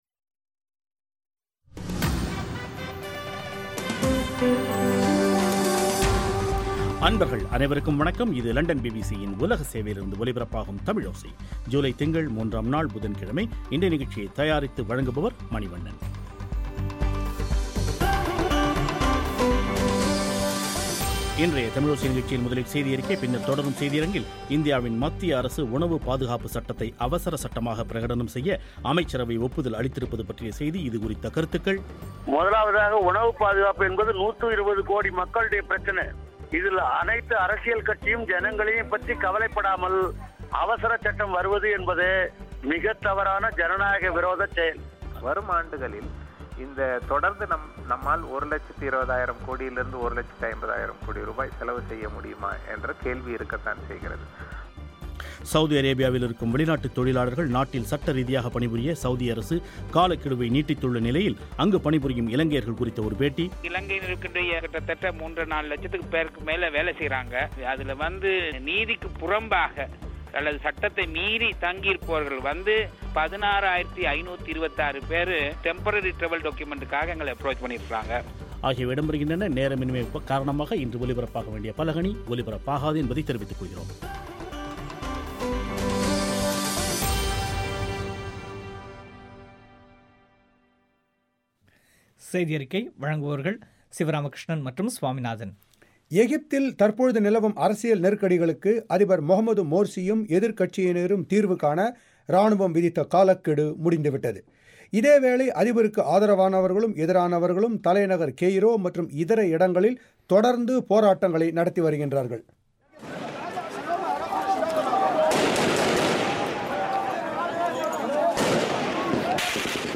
இன்றைய தமிழோசை நிகழ்ச்சியில் முதலில் செய்தி அறிக்கை பின்னர் தொடரும் செய்தி அரங்கில்